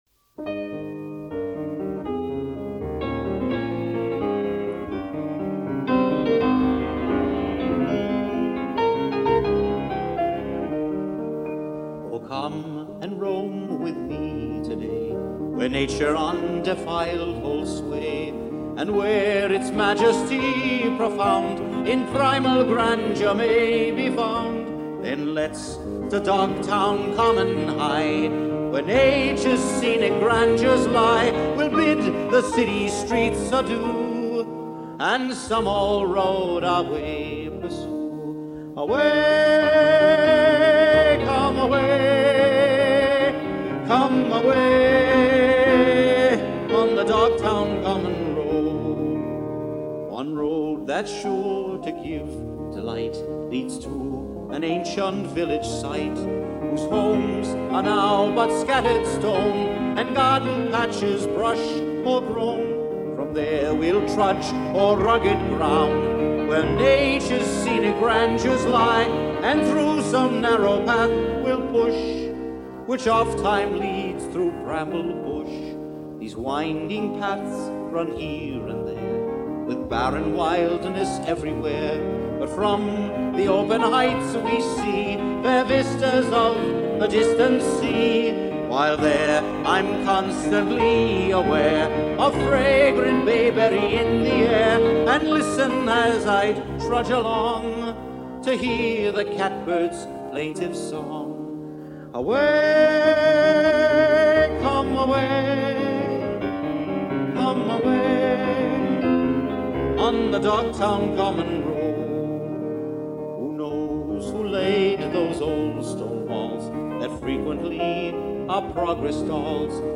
Both recordings were made in Dublin.